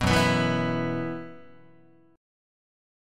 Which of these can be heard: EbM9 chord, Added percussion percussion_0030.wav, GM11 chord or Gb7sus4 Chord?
Gb7sus4 Chord